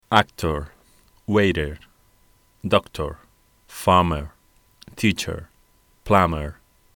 Pronunciation
Did you notice how, although they have different spellings, the last sound was exactly the same?
This sound can be represented by this symbol /ə/ and is very common in English.